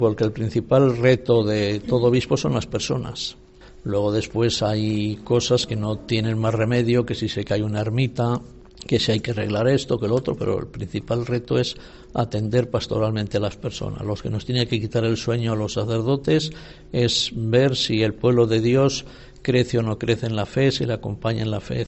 Han sido declaraciones de monseñor Jesús Rico durante el tradicional encuentro navideño con los medios de comunicación, el primero para el obispo Rico.